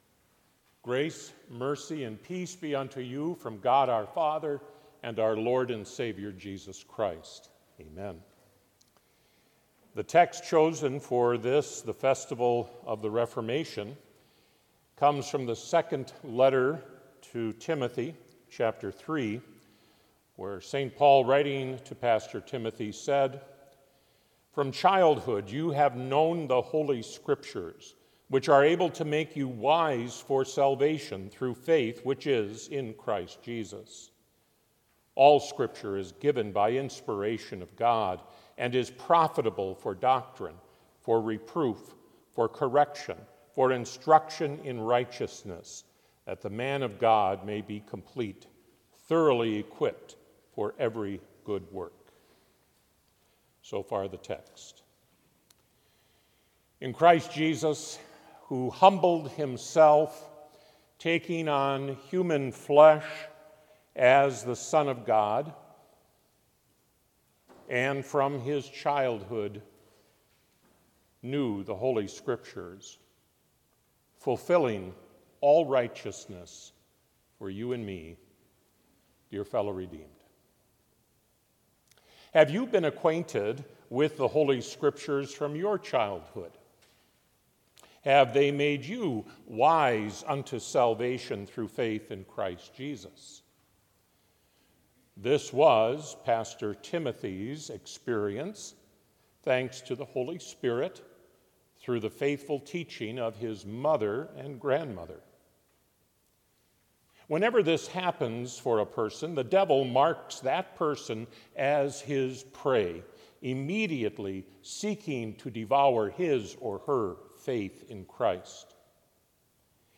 Complete service audio for Chapel - Thursday, October 31, 2024